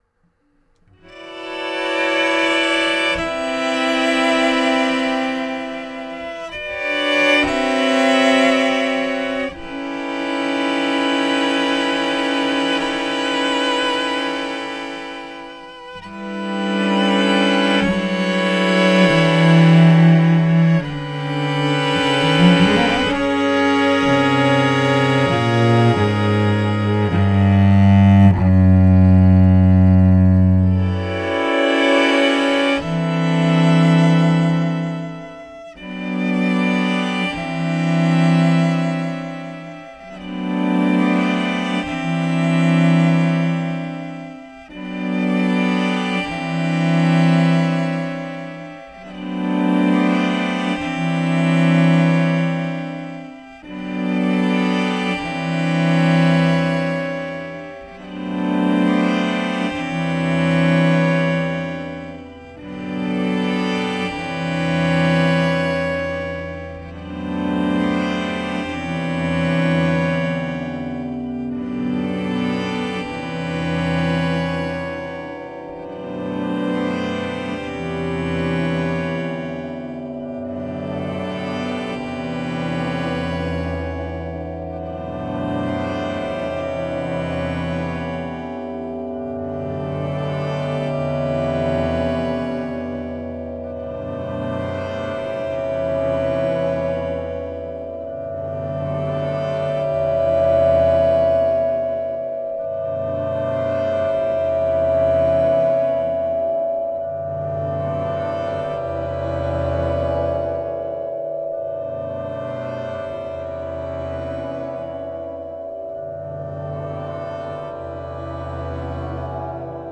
The composition is split into two parts and accompanied by live cello and bass guitar and begins with the birdsong melody in it’s original form.
Closing+composiiton+without+live+cello.mp3